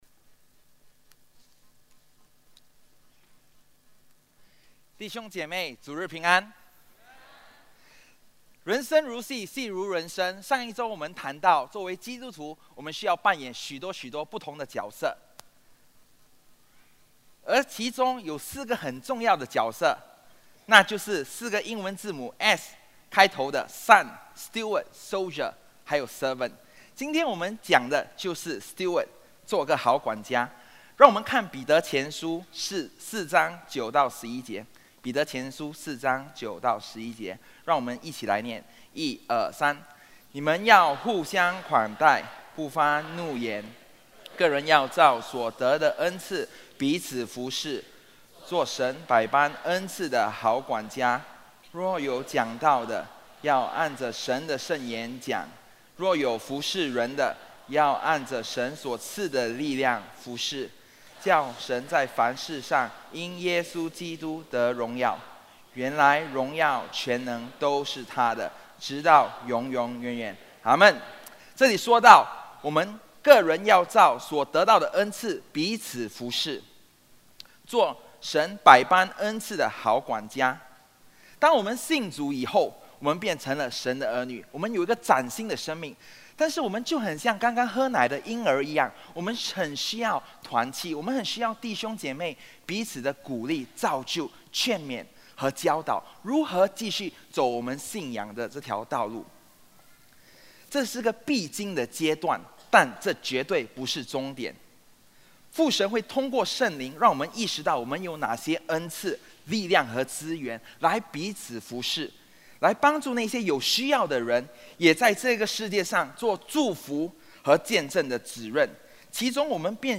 主日证道 | 基督徒系列二：好管家